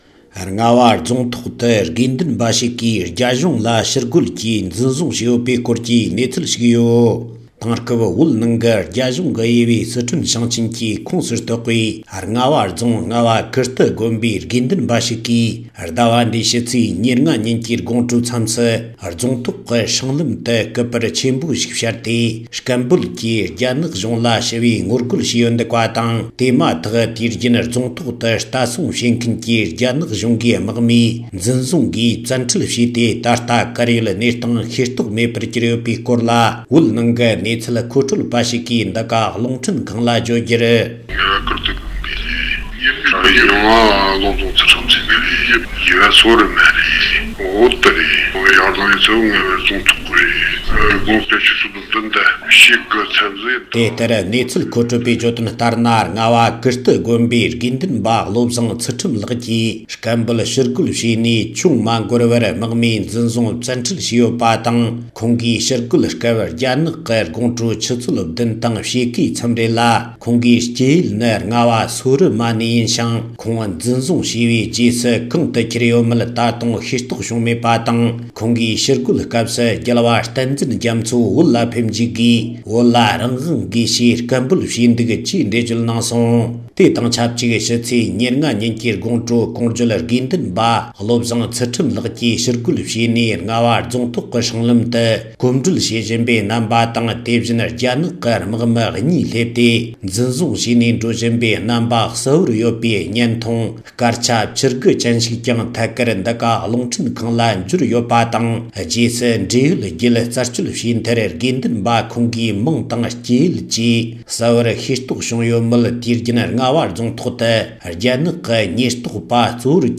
བོད་ནས་བཏང་འབྱོར།
སྒྲ་ལྡན་གསར་འགྱུར།